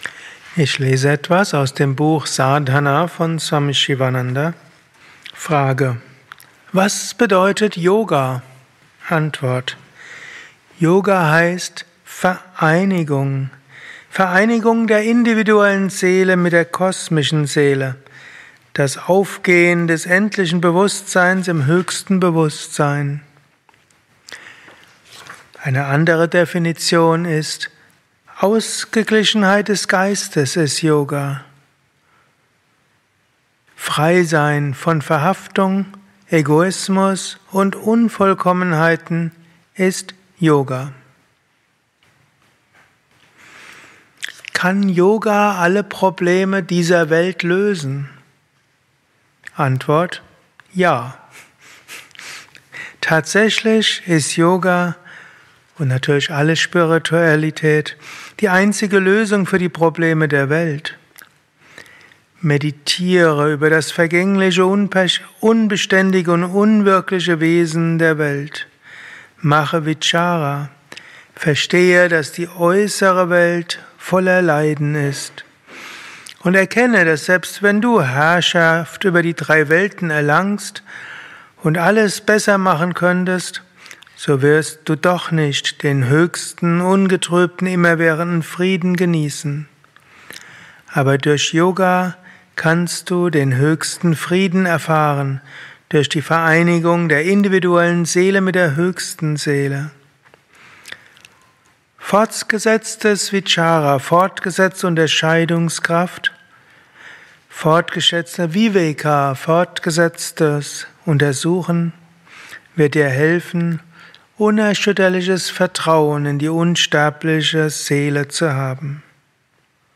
Höre eine kurze Lesung aus dem Buch Sadhana von Swami Sivananda über “Was ist Yoga” als Inspiration für den heutigen Tag von und mit
eine Aufnahme während eines Satsangs gehalten nach einer Meditation im Yoga Vidya Ashram Bad Meinberg.